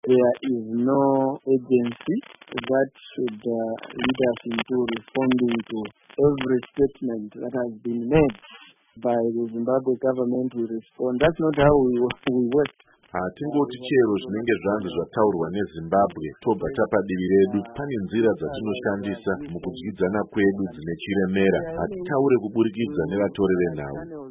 Mashoko aVaCornelius Mweetwa